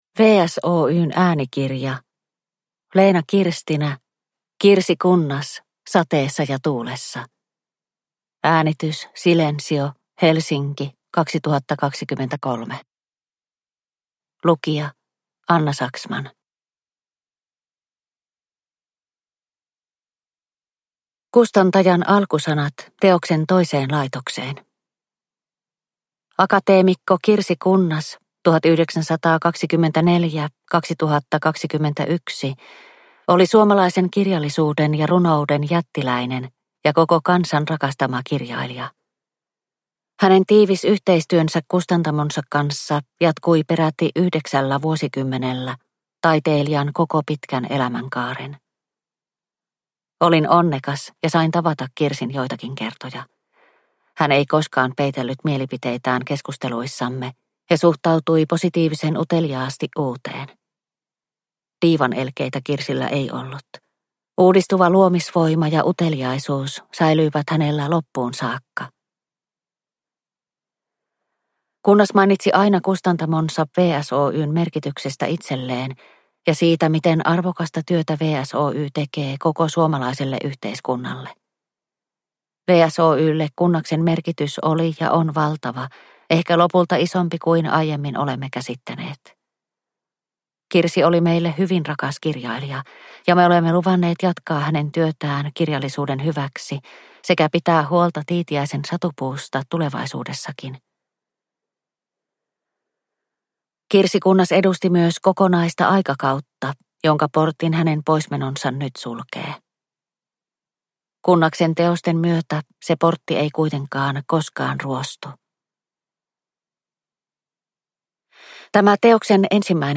Kirsi Kunnas - sateessa ja tuulessa – Ljudbok – Laddas ner